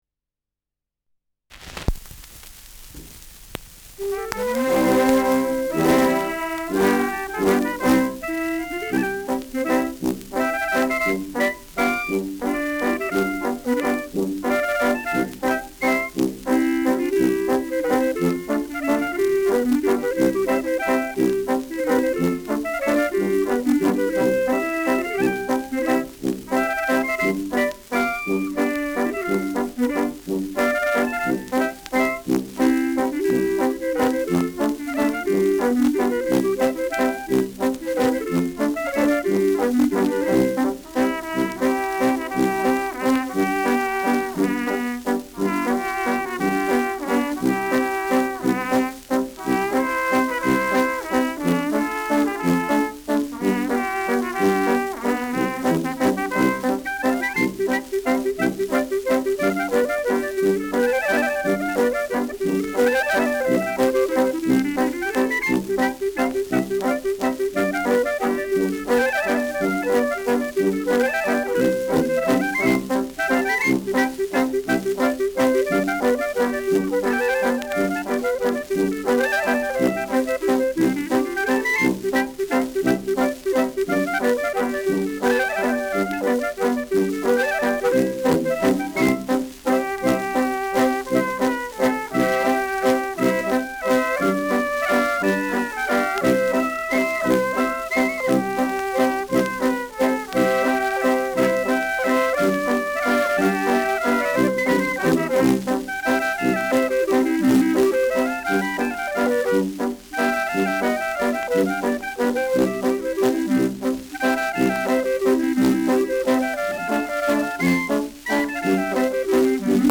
Schellackplatte
Leicht abgespielt : Gelegentliches Knacken : Dumpfer Klang